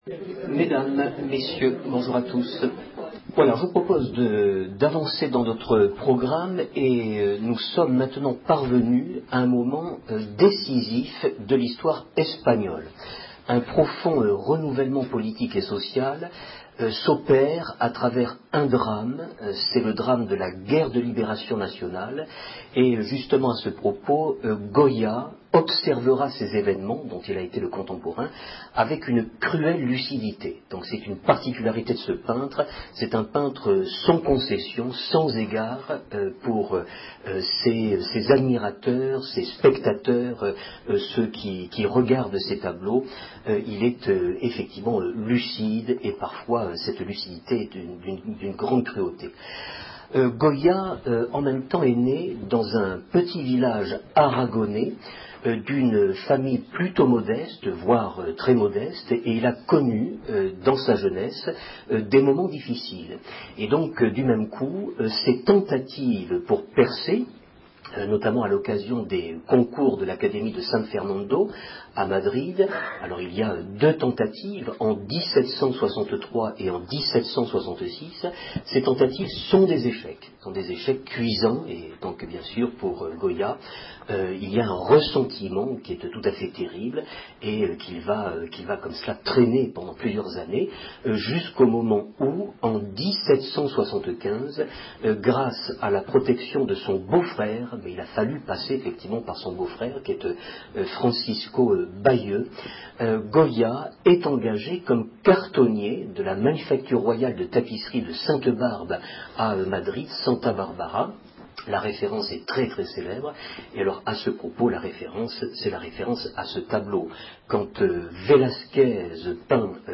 Enregistrement audio du cours